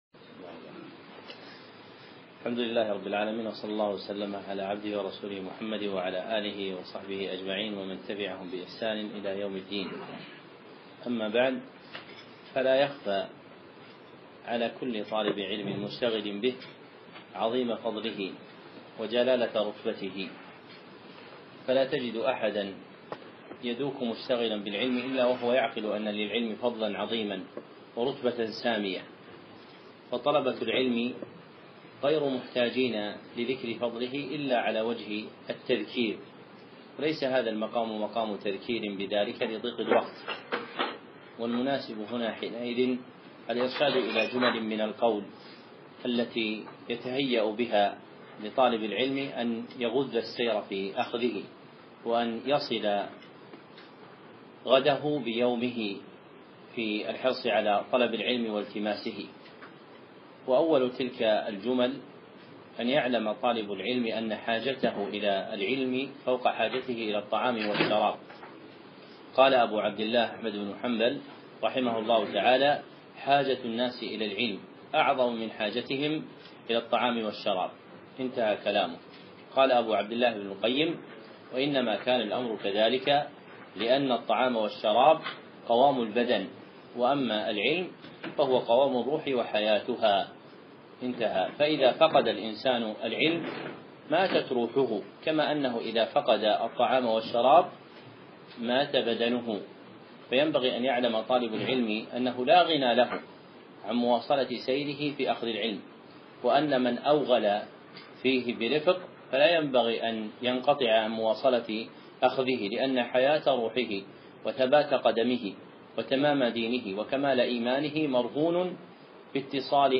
154. كلمة في الحث على طلب العلم